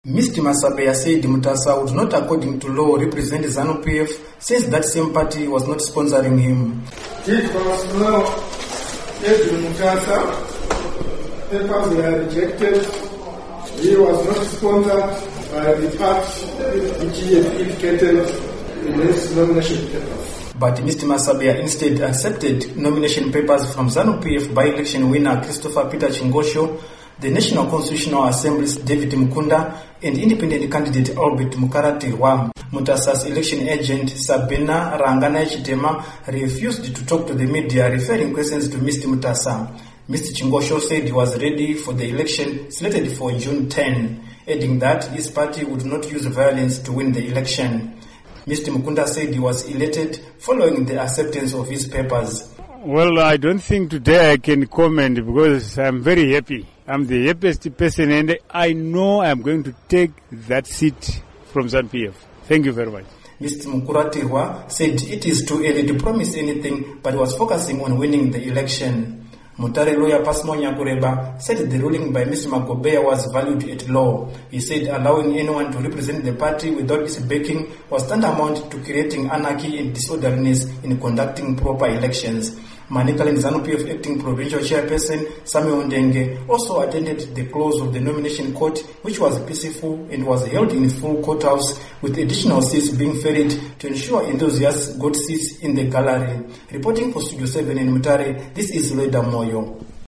Report on Rejection of Didymus Mutasa's Papers